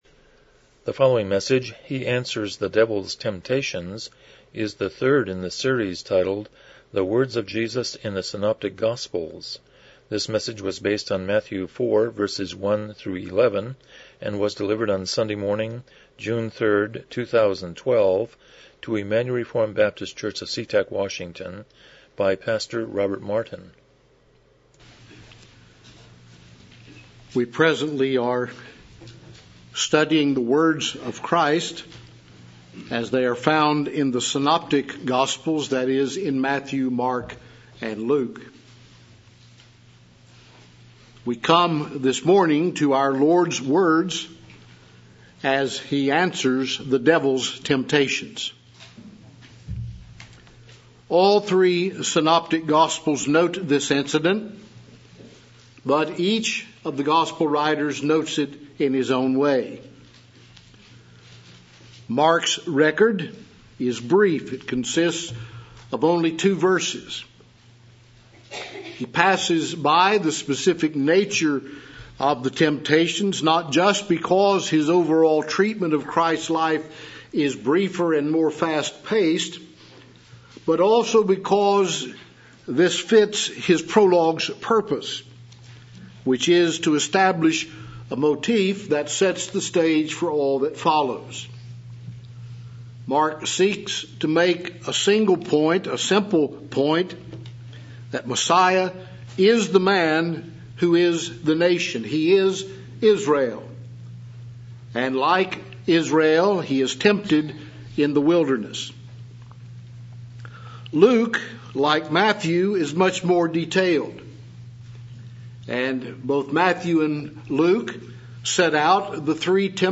Passage: Matthew 4:1-11 Service Type: Morning Worship